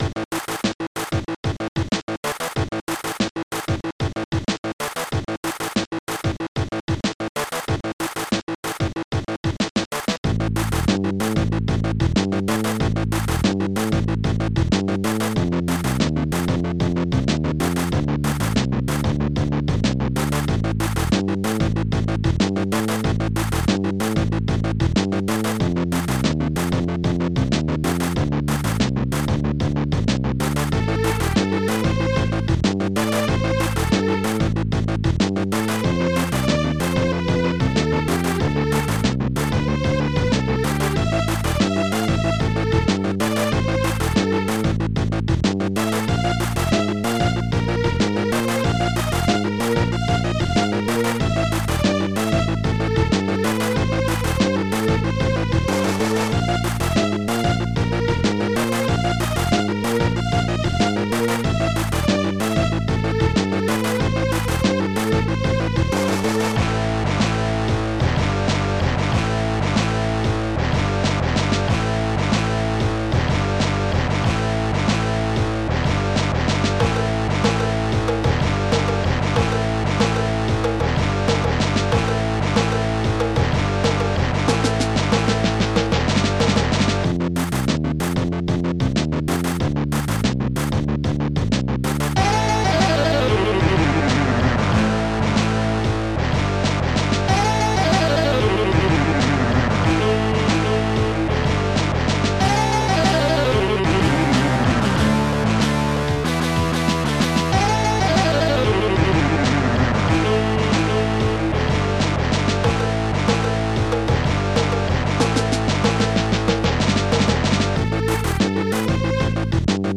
Protracker Module  |  2013-12-12  |  80KB  |  2 channels  |  44,100 sample rate  |  3 minutes, 9 seconds
st-88:bassdrum8
st-88:strings8
st-88:guitar4
st-88:snare13
st-88:tomdrum
st-88:saxophone